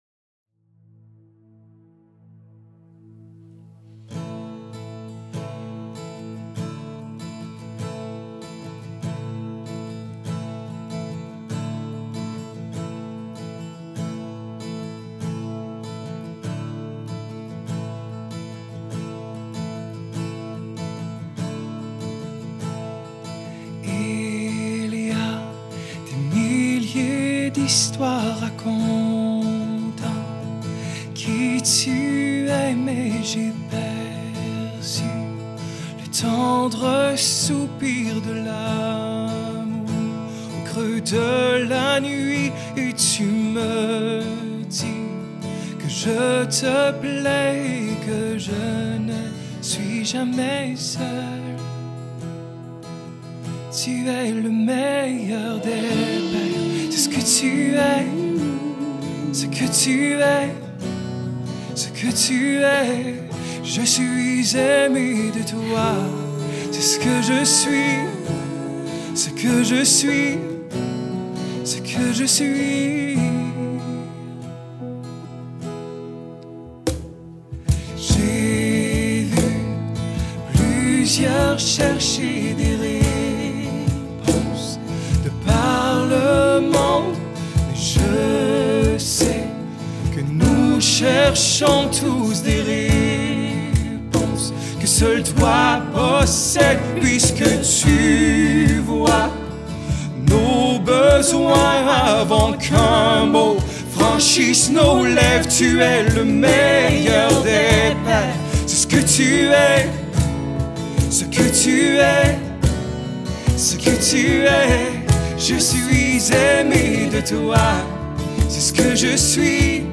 35 просмотров 73 прослушивания 0 скачиваний BPM: 75